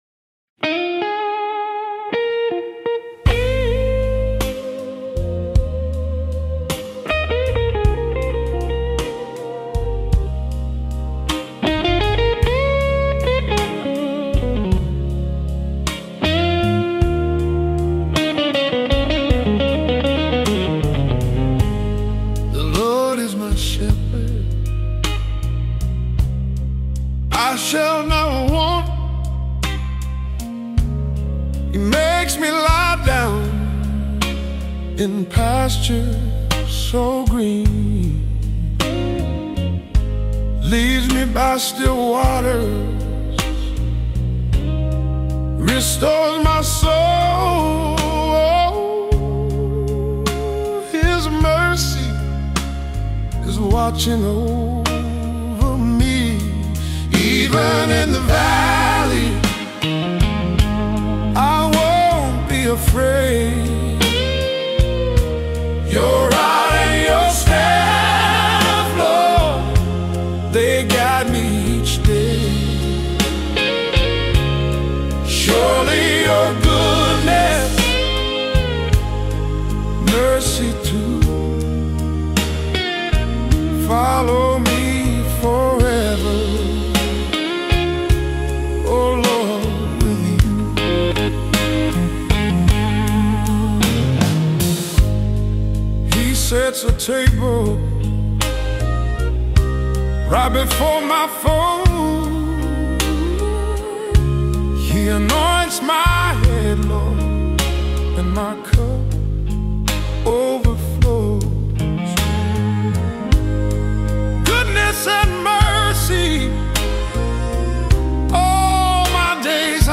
Psalm 23 Blues - Gospel Blues